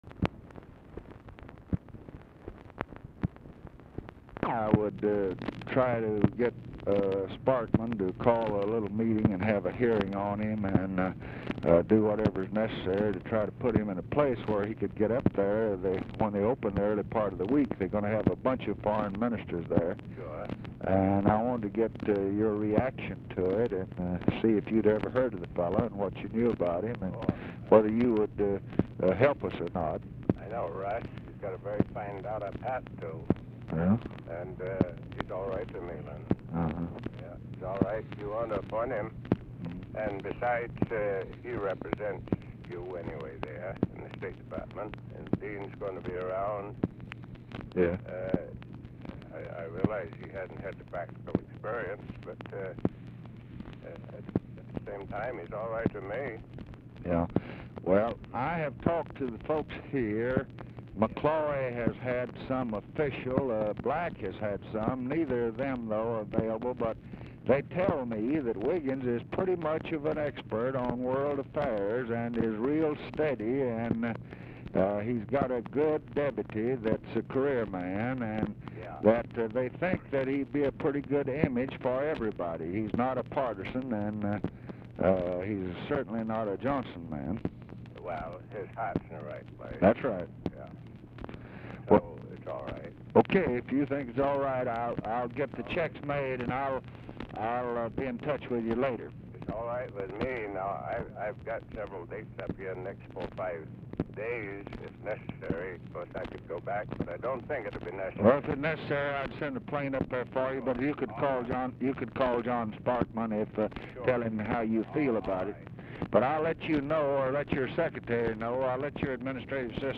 Telephone conversation # 13417, sound recording, LBJ and GEORGE AIKEN, 9/26/1968, 12:40PM | Discover LBJ
Format Dictation belt
Location Of Speaker 1 Oval Office or unknown location